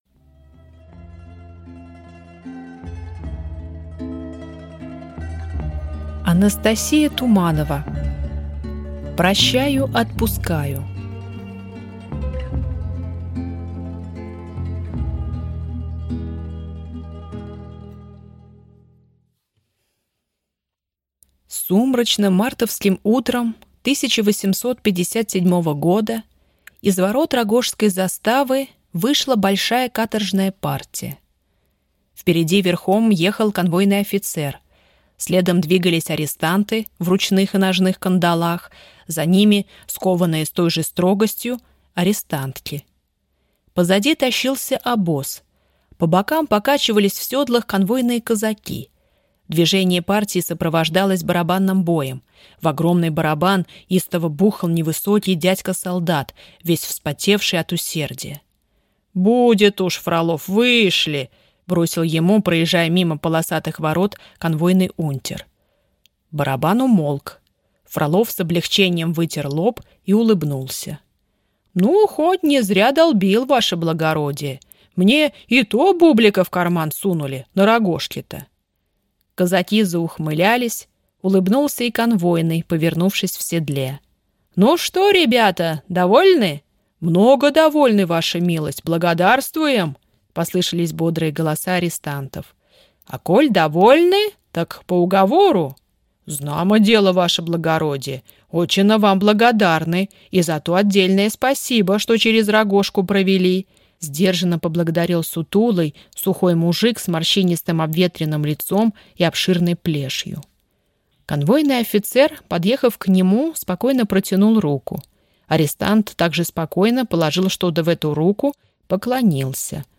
Аудиокнига Прощаю – отпускаю | Библиотека аудиокниг